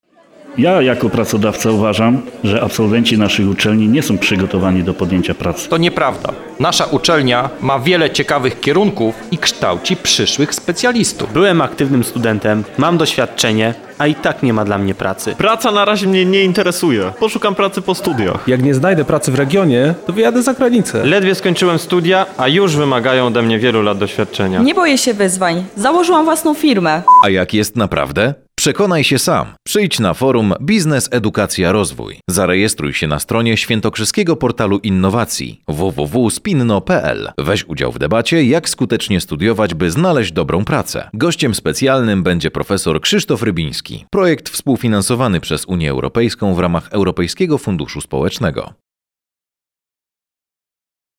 Spot radiowy zapraszający na Forum Biznes-Edukacja-Rozwój